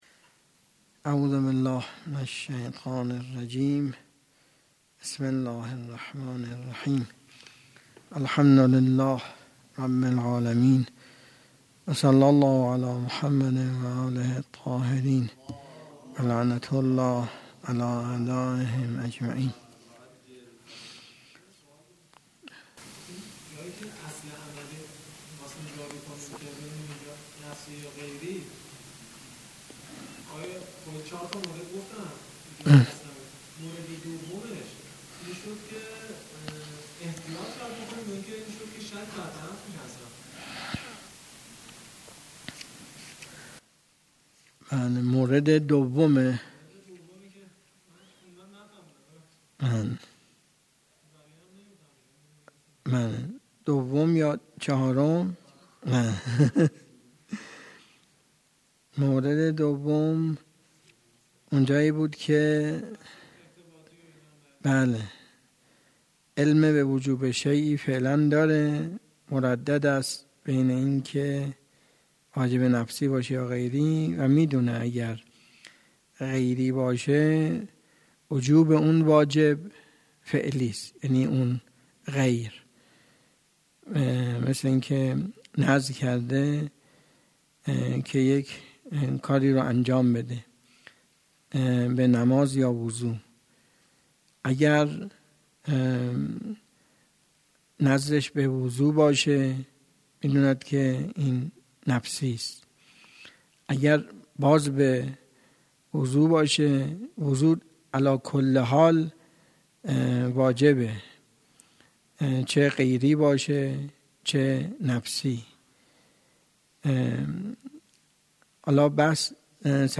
درس خارج اصول
سخنرانی